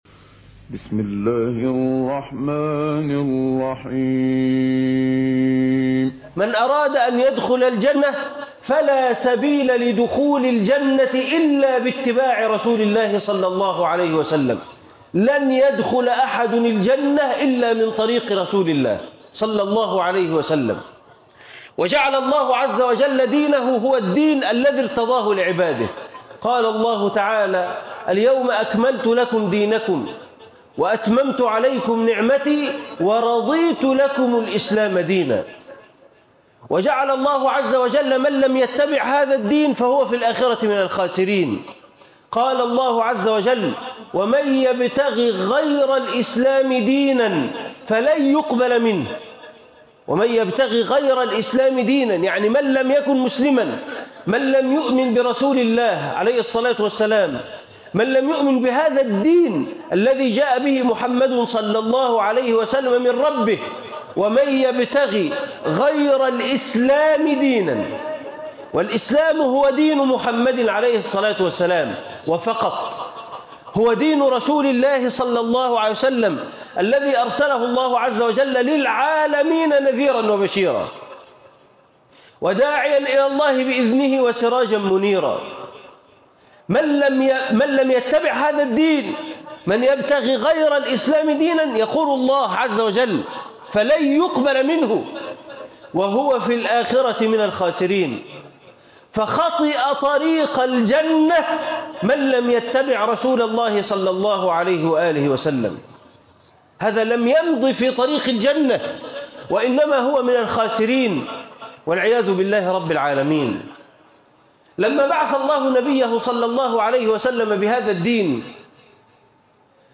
هو الذي أرسل رسوله بالهدى | خطبة جمعةي